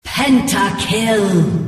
Download League of Legends Pentakill sound effect for free.